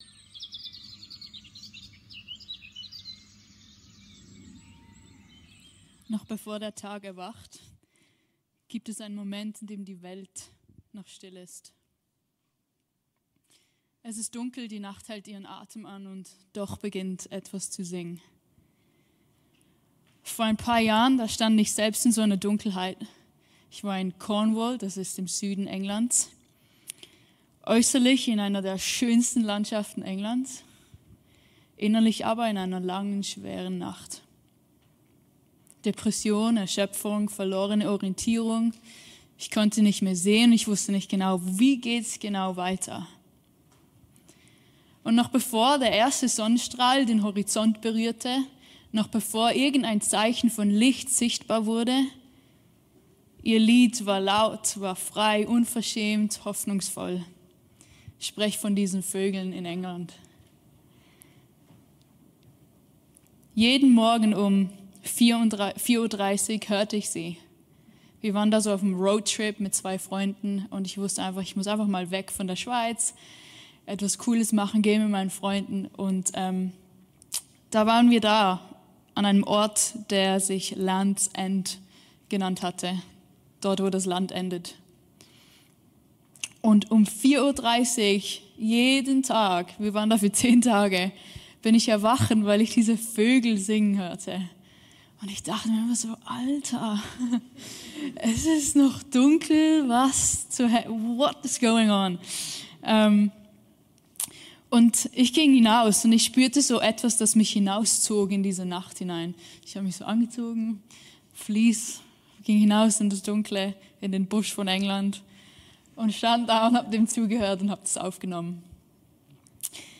Hier erscheinen meist wöchentlich die Predigten aus dem Sonntags-Gottesdienst des CVJM Stuttgart